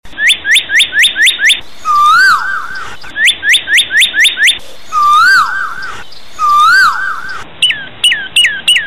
Bird singing sound ringtone free download
Animals sounds